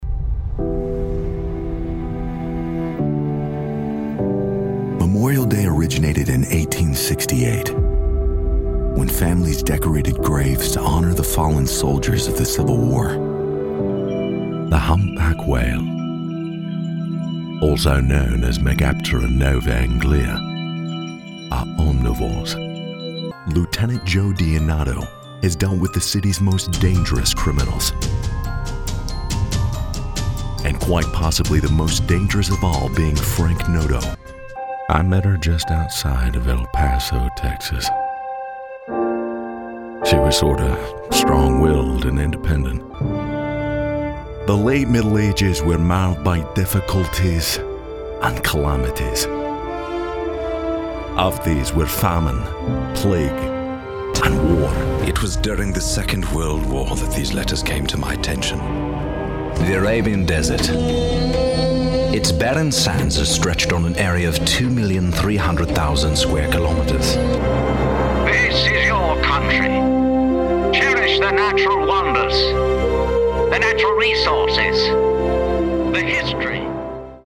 Natürlich, Unverwechselbar, Zugänglich, Vielseitig, Warm
Audioguide